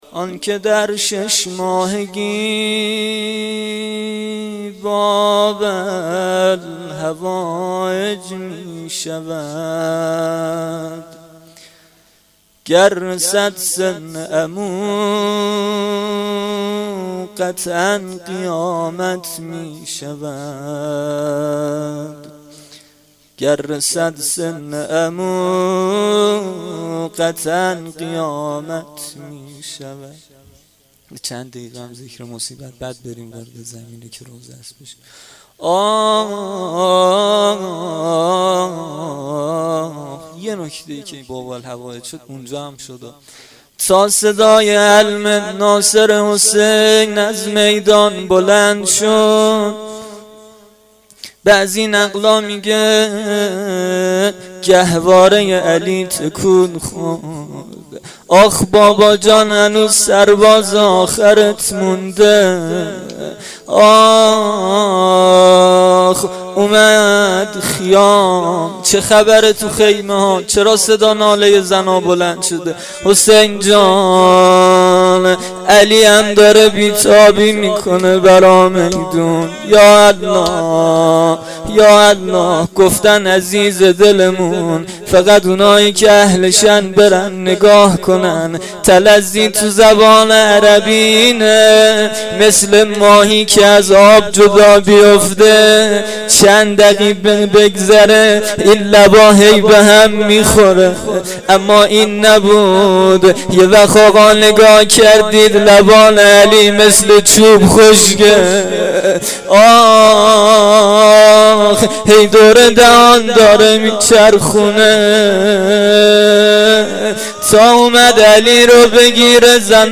روضه شب هفتم محرم 93